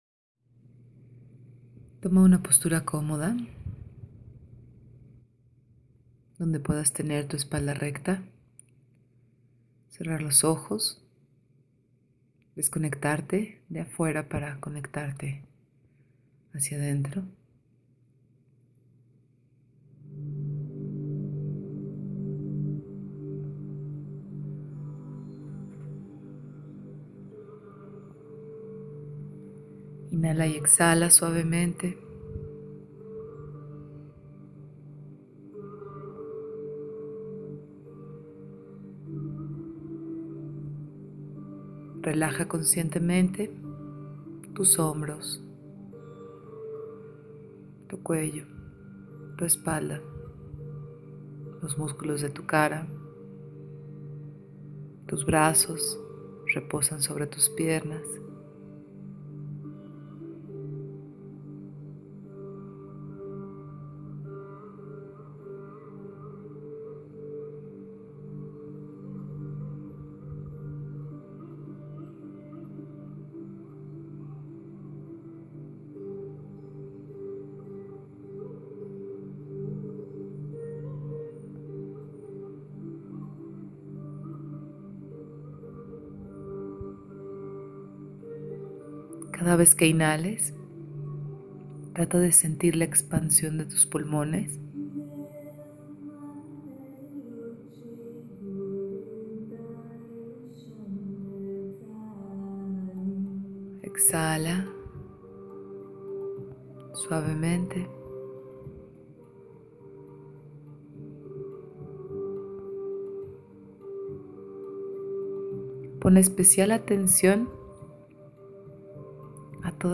¡Medita! te dejamos con esta meditación que dura 11 minutos.